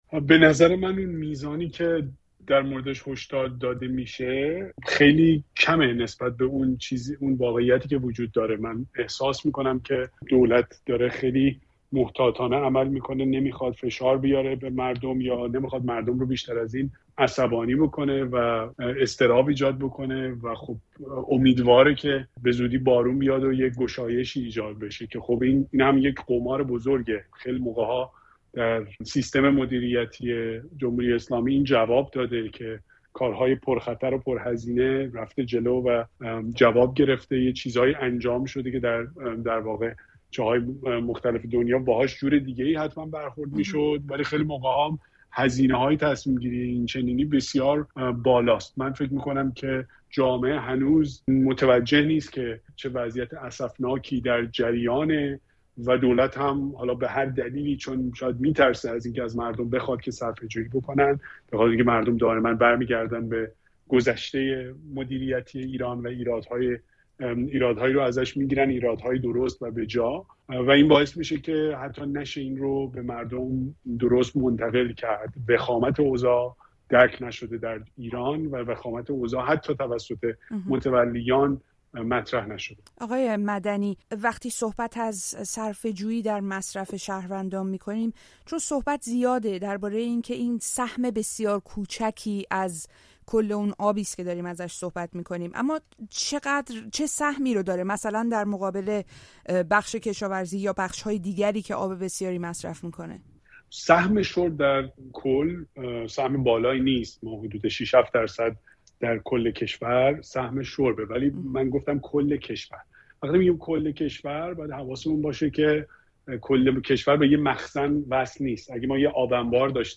گفت‌وگو با کاوه مدنی؛ بحران کم‌آبی چقدر جدی است و از دست مردم چه برمی‌آید؟